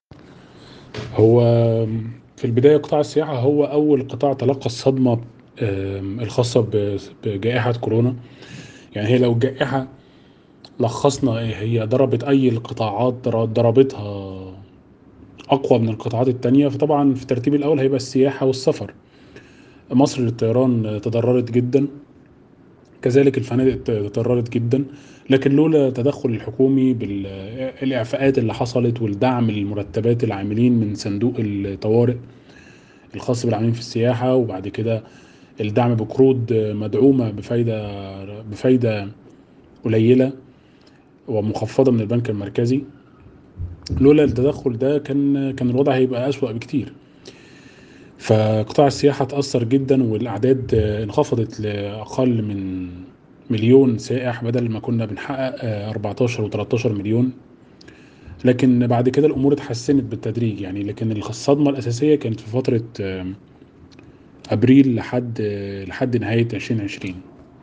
حوار
محلل اقتصادي